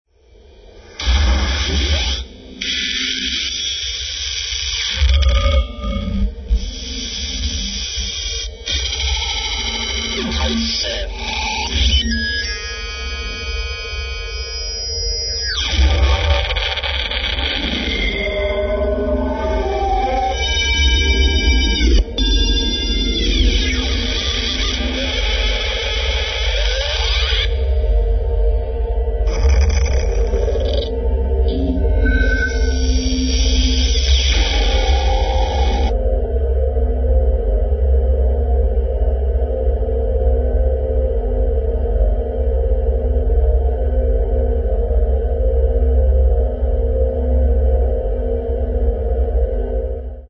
Great monotonous drones & massive sound